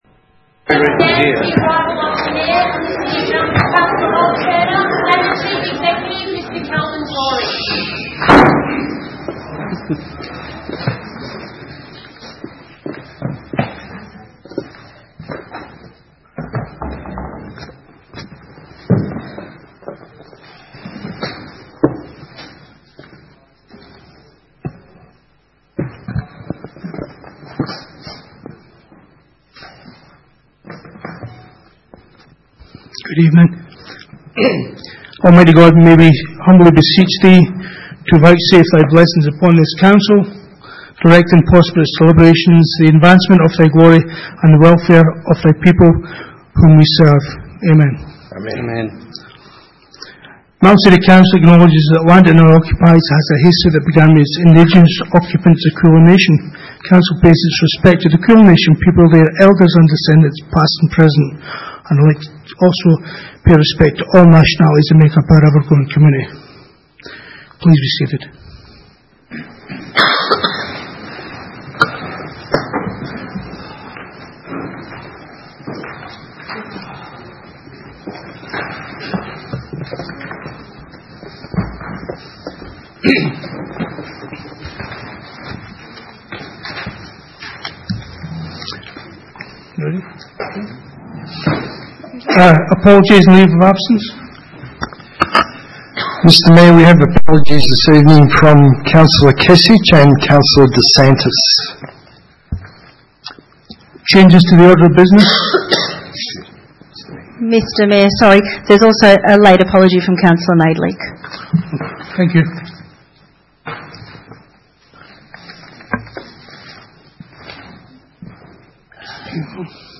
Ordinary Meeting 20 August 2018